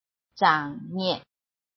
拼音查詢：【詔安腔】zhang ~請點選不同聲調拼音聽聽看!(例字漢字部分屬參考性質)